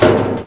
klonk2.mp3